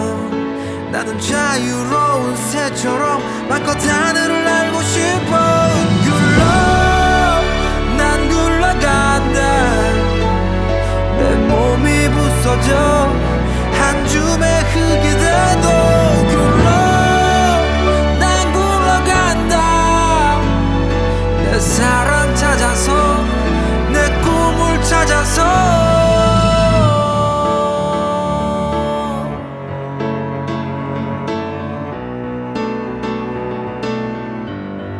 2012-04-20 Жанр: Поп музыка Длительность